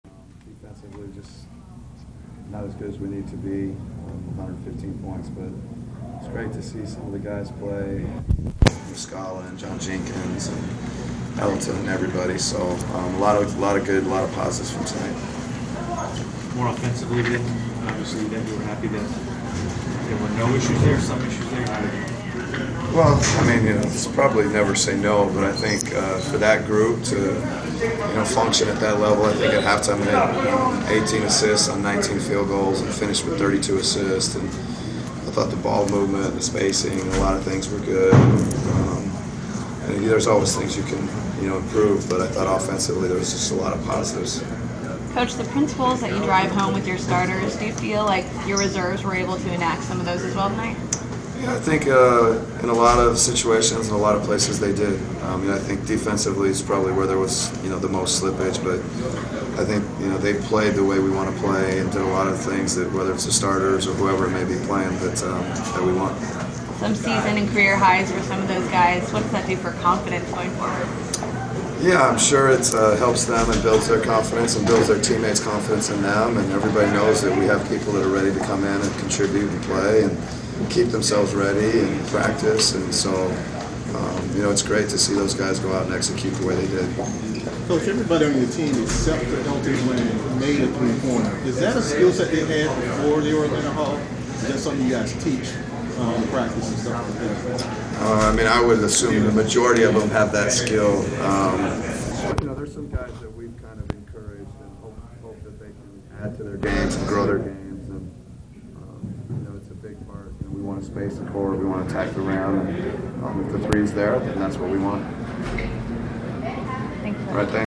Inside the Inquirer: Postgame presser with Atlanta Hawks’ head coach Mike Budenholzer (3/28/15)